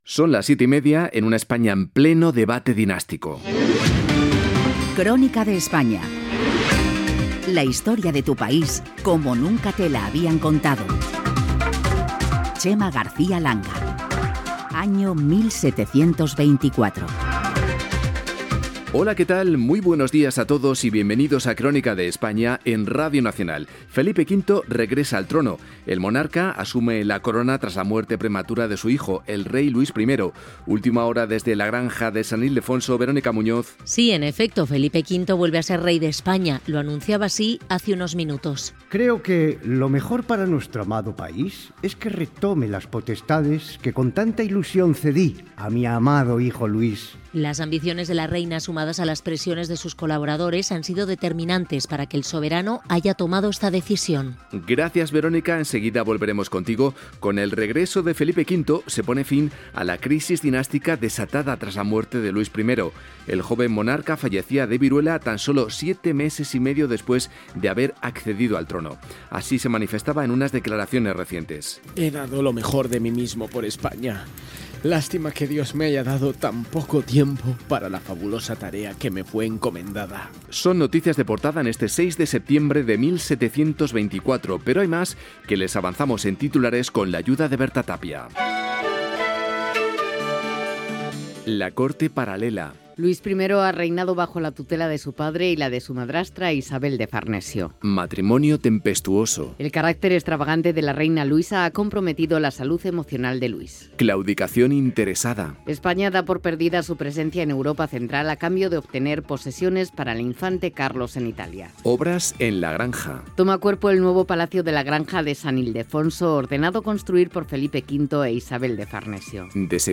Careta del programa, benvinguda, Recreació radiofònica, en forma d'informatiu, de la història espanyola el dia 6 de setembre de 1724. El retorn de Felip V